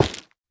Minecraft Version Minecraft Version snapshot Latest Release | Latest Snapshot snapshot / assets / minecraft / sounds / block / stem / break5.ogg Compare With Compare With Latest Release | Latest Snapshot
break5.ogg